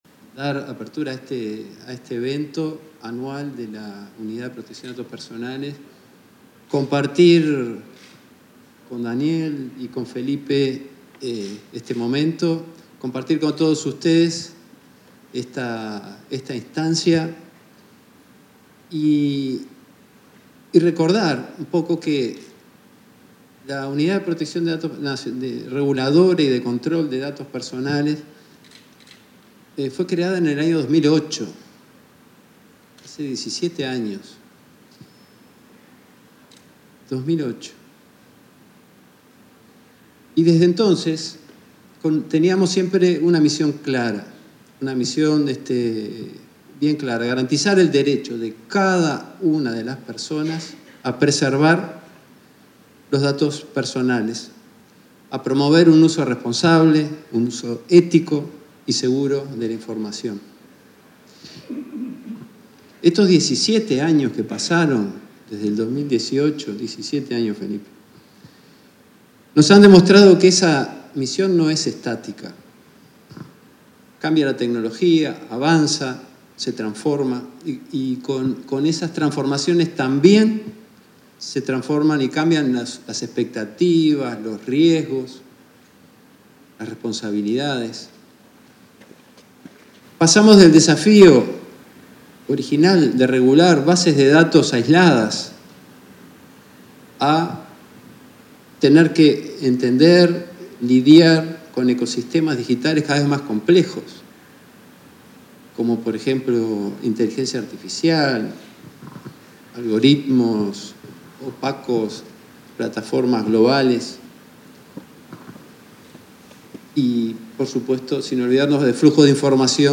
Palabras de autoridades de Agesic y URCDP en evento anual de Protección de Datos Personales